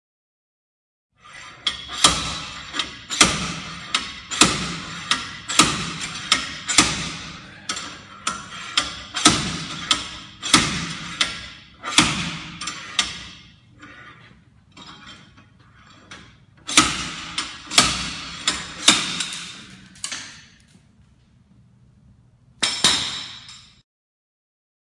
敲击金属锤子 滑动锤子
描述：击中金属锤击滑锤
Tag: 锤击 金属 幻灯片 创下